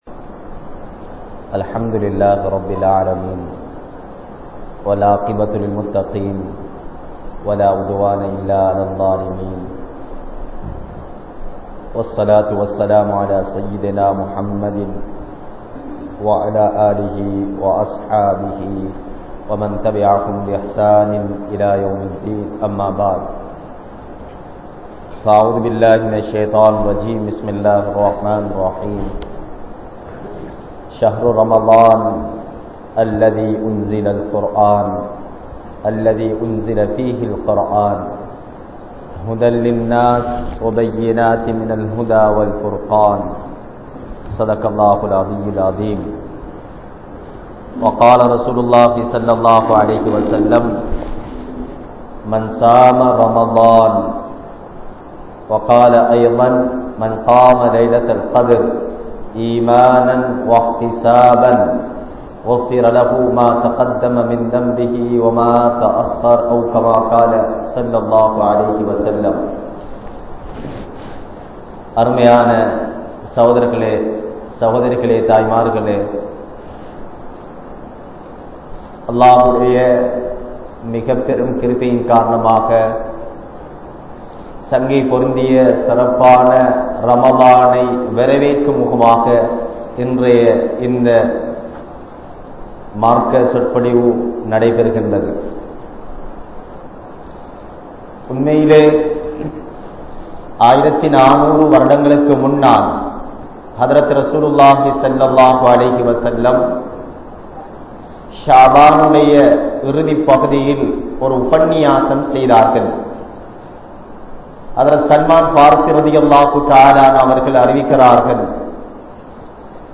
Ramalaanai Vara Veatpoam (ரமழானை வரவேற்போம்) | Audio Bayans | All Ceylon Muslim Youth Community | Addalaichenai
Masjithur Ravaha